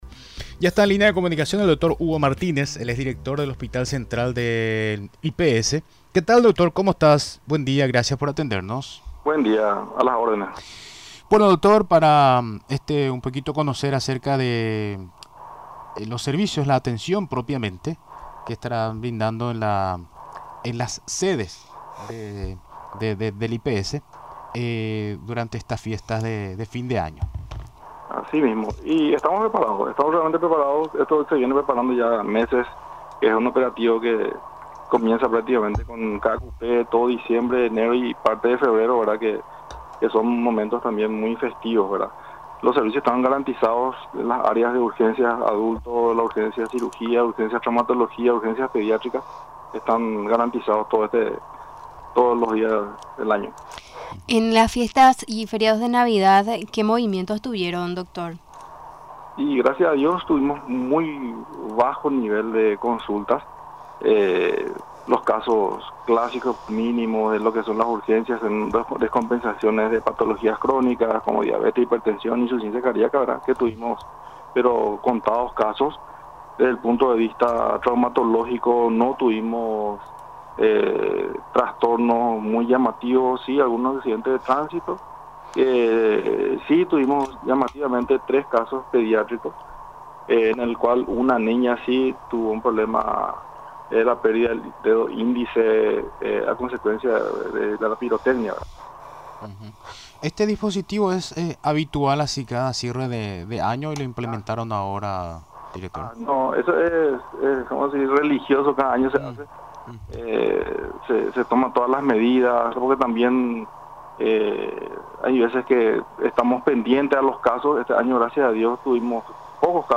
Durante la entrevista en Radio Nacional del Paraguay, explicó los servicios que cuenta el Instituto de Previsión Social.